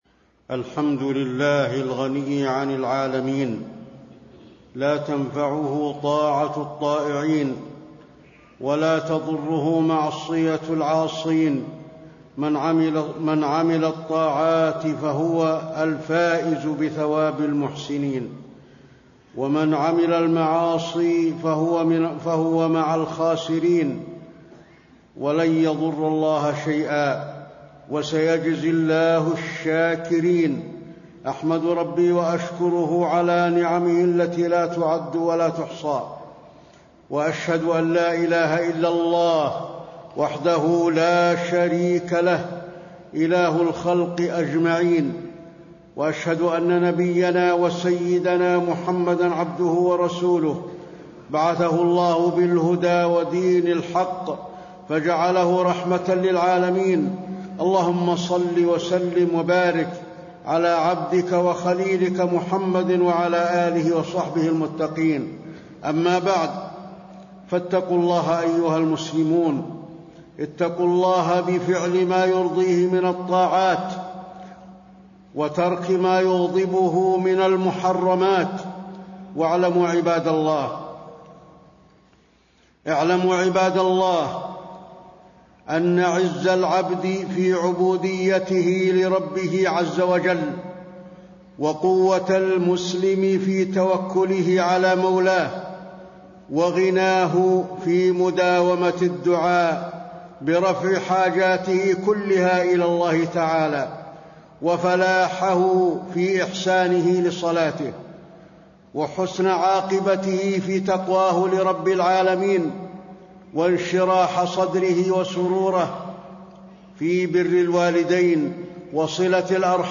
تاريخ النشر ٢٦ ذو القعدة ١٤٣٣ هـ المكان: المسجد النبوي الشيخ: فضيلة الشيخ د. علي بن عبدالرحمن الحذيفي فضيلة الشيخ د. علي بن عبدالرحمن الحذيفي عز العبد في عبوديته لله تعالى